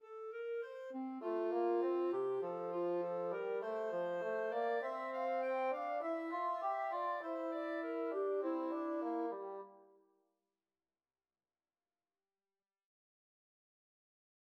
베토벤 교향곡 4번, 1악장 카논 구절